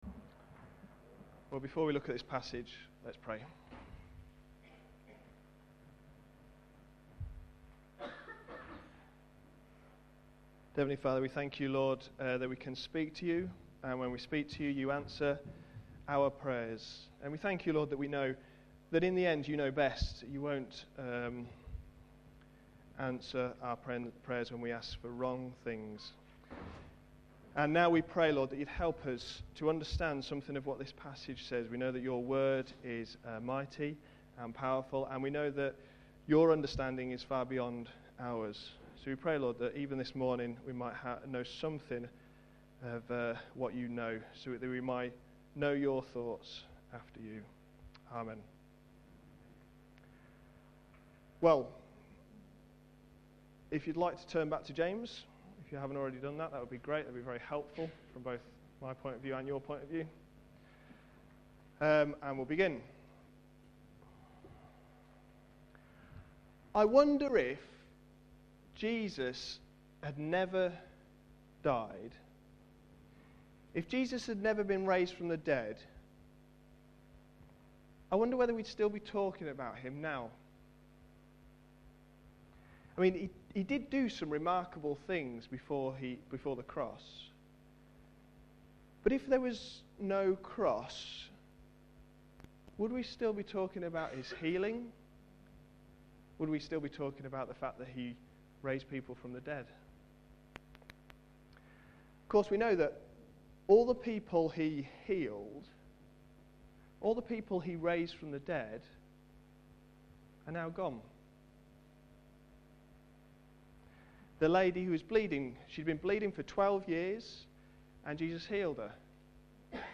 A sermon preached on 21st November, 2010.